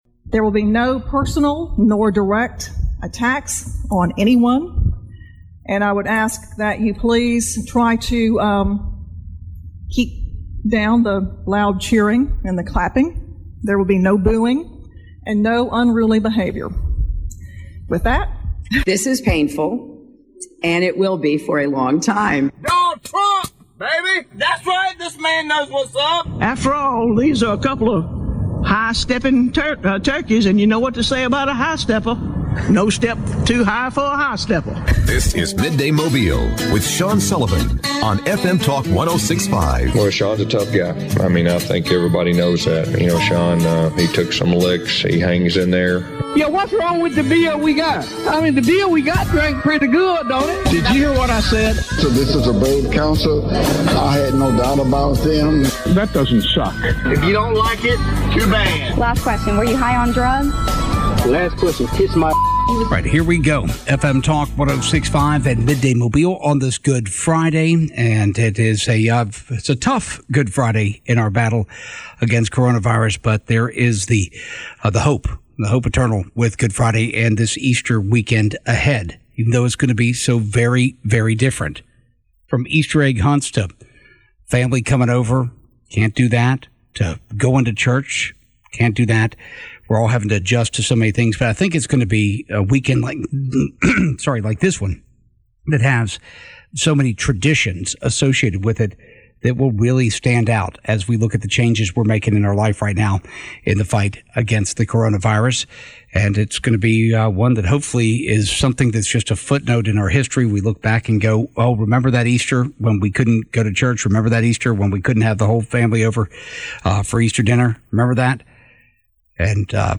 talks with Mobile County Commissioner Jerry Carl and Congressman Bradley Byrne